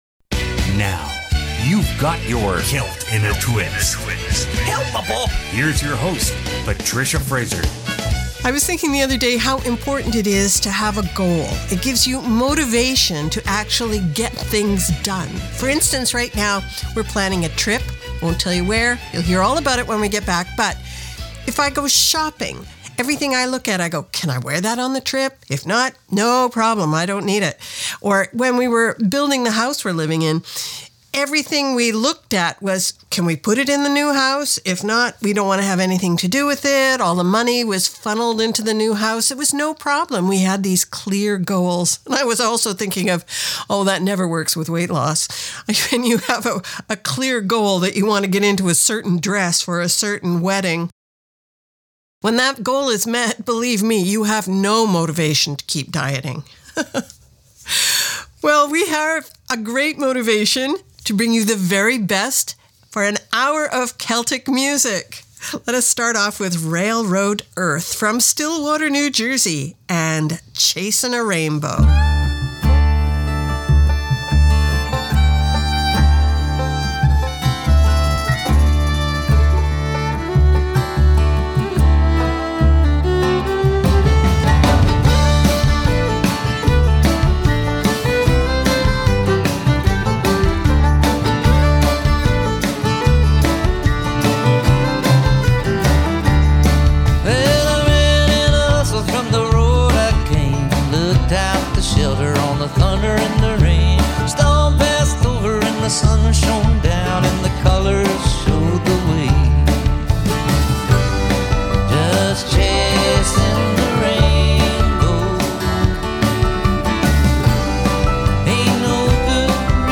Canada's Contemporary Celtic Hour